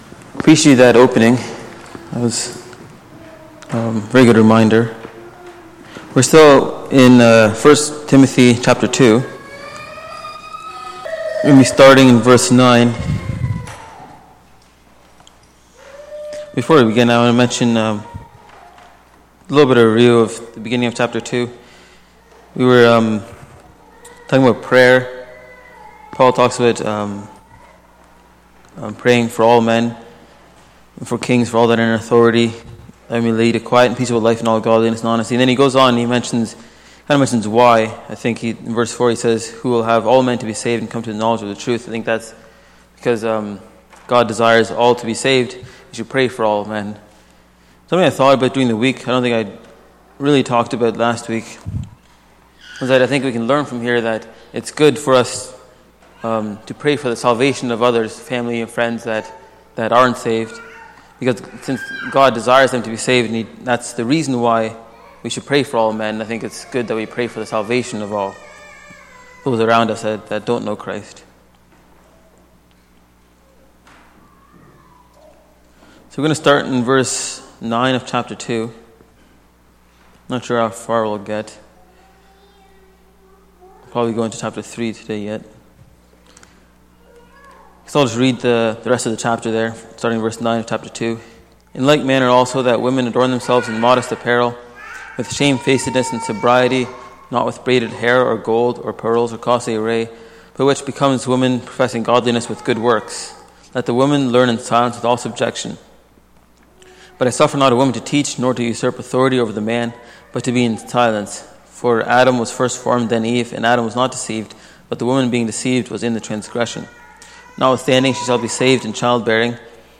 Series: Sunday Morning Bible Study